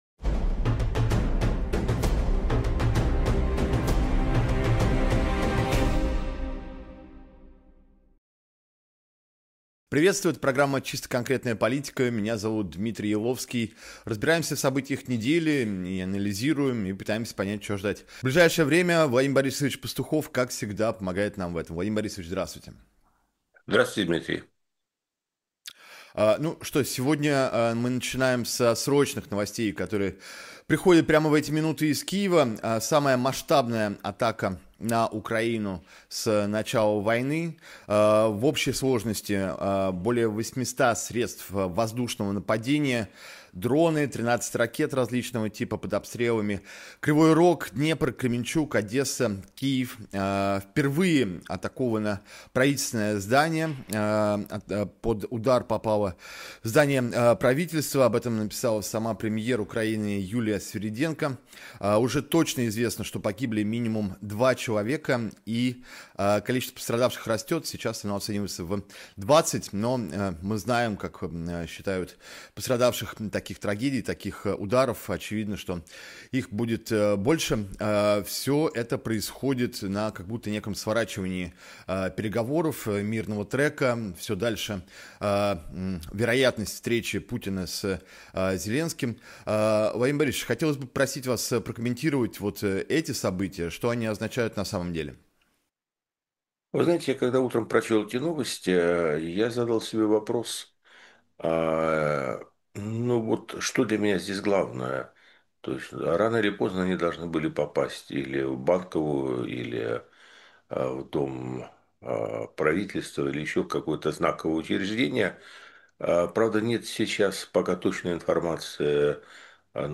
Владимир Пастухов политолог